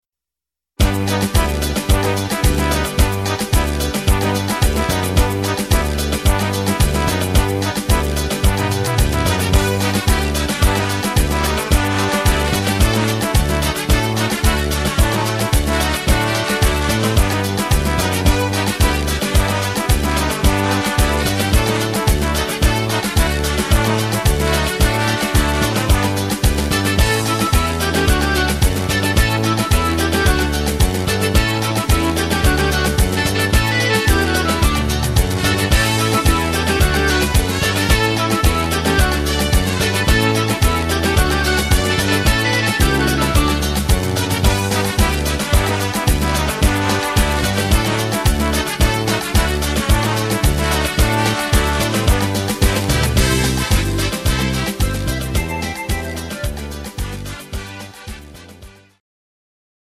instrumental Orchester